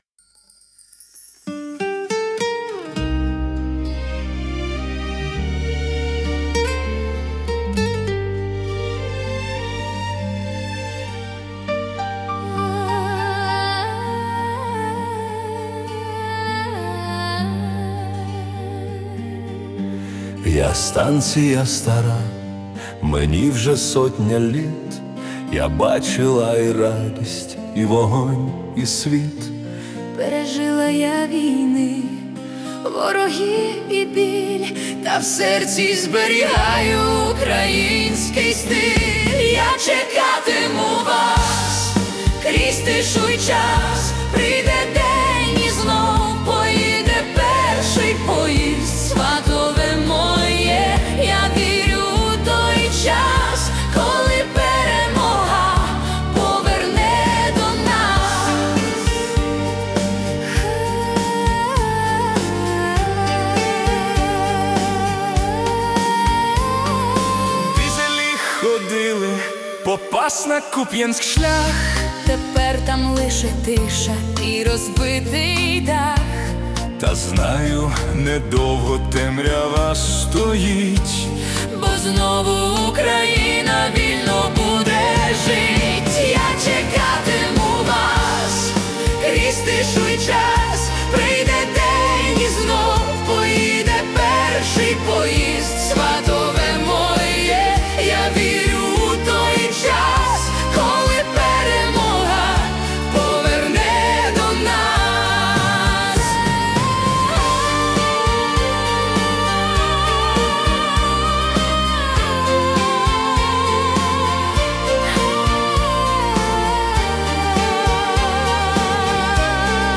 Ukrainian Ballad-March